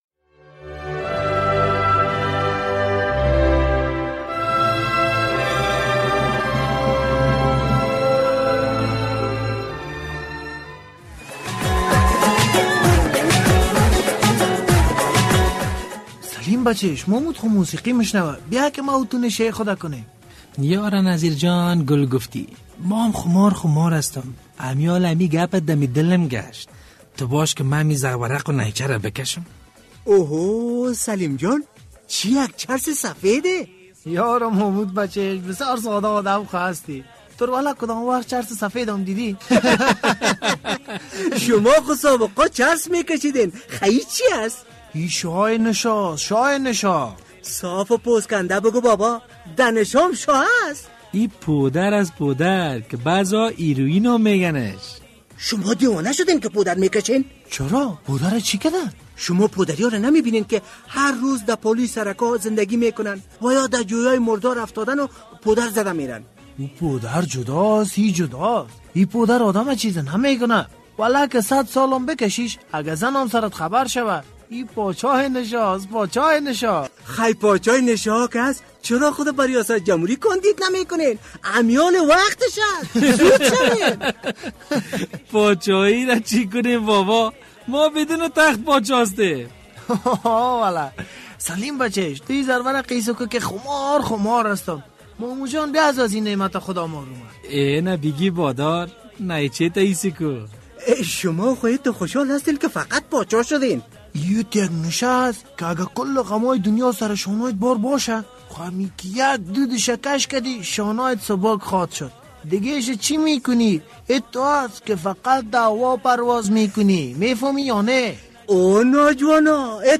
برای شنیدن این درامه روی لینک زیر کلیک نماید!